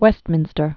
West·min·ster
(wĕstmĭnstər) or Officially City of Westminster.